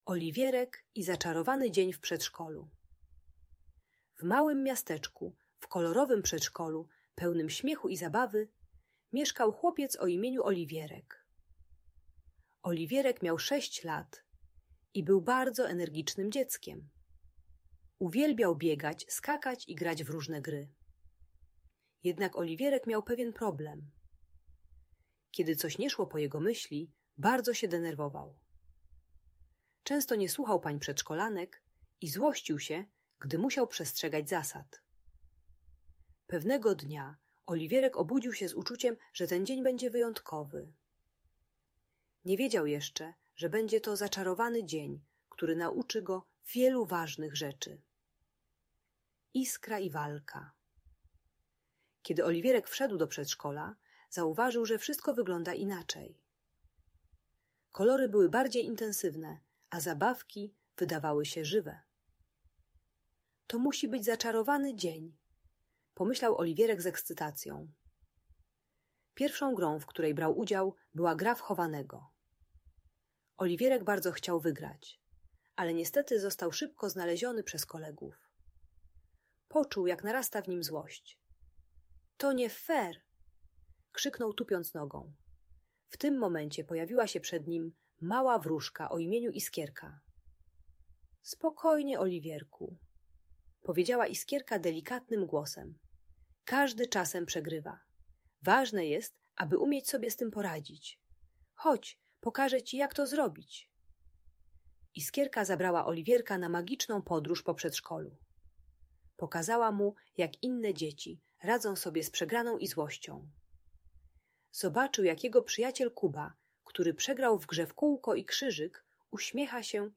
Oliwierek i Zaczarowany Dzień w Przedszkolu - Audiobajka